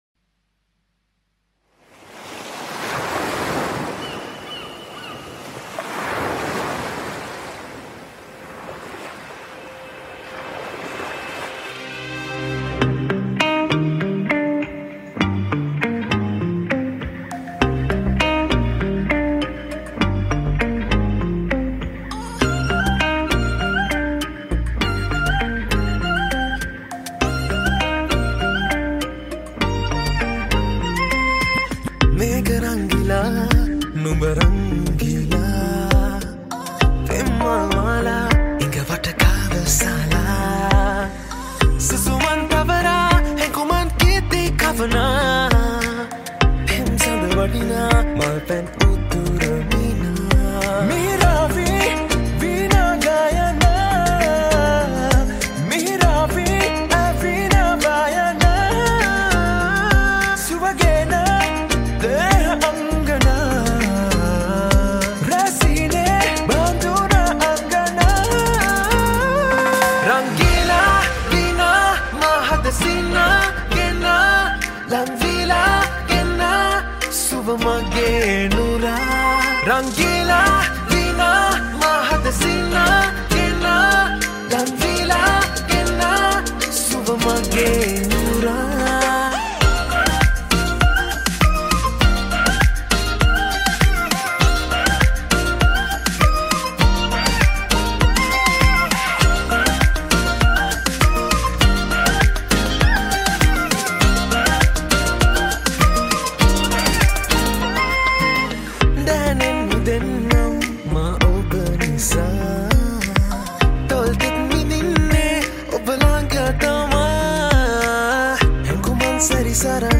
High quality Sri Lankan remix MP3 (4.1).
remix